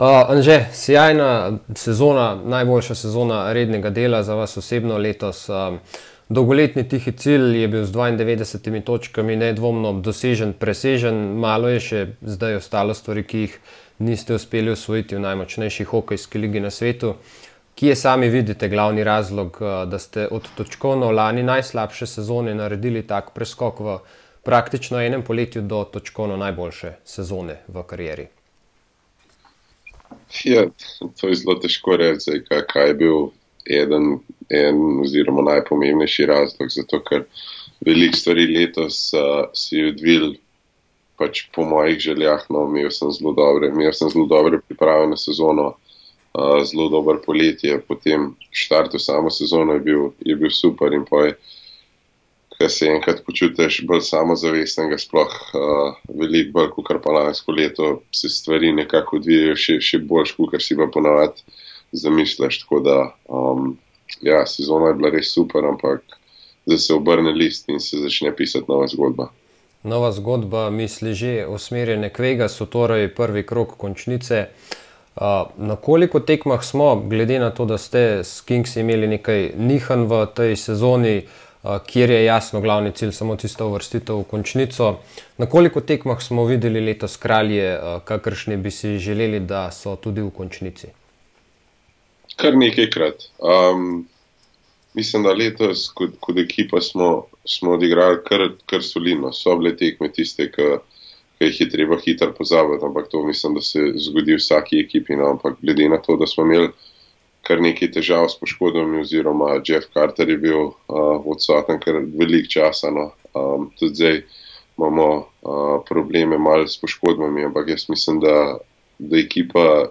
Pogovor z Anžetom Kopitarjem: